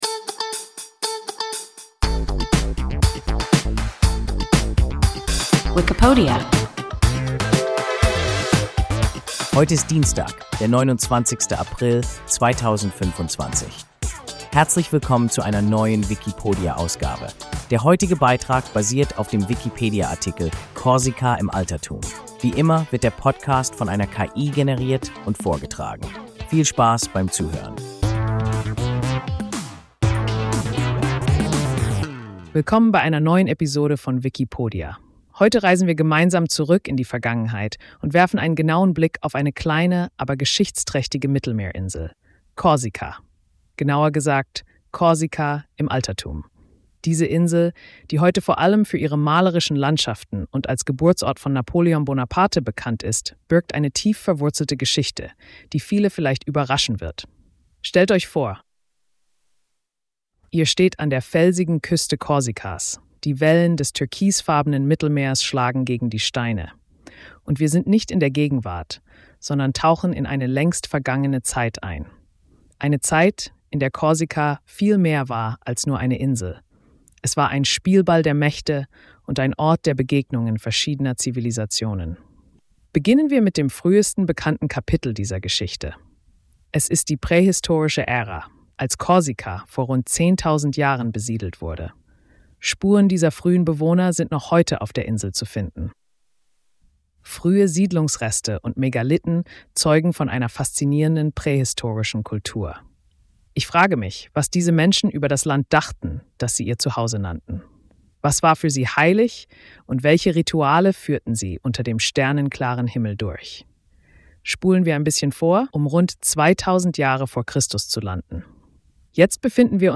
Korsika im Altertum – WIKIPODIA – ein KI Podcast